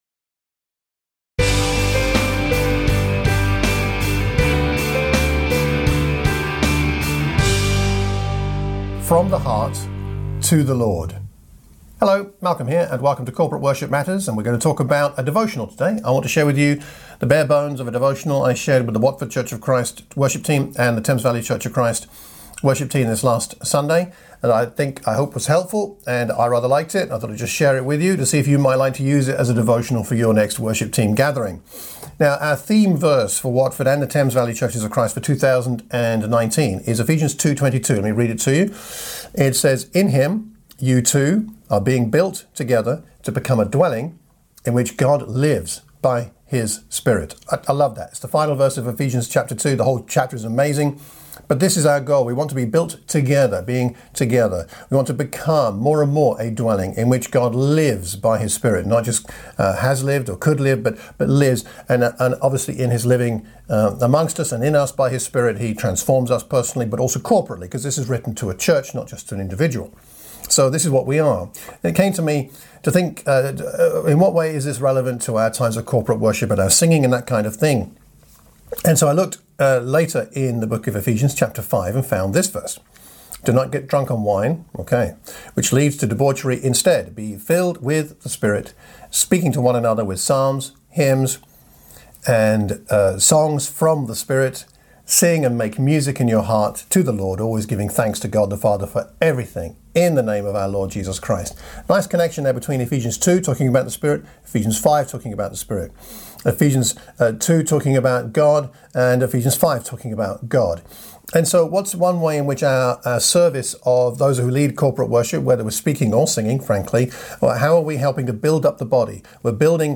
I shared these thoughts with the worship teams of the Watford and Thames Valley churches in a short devotional before we lead the worship.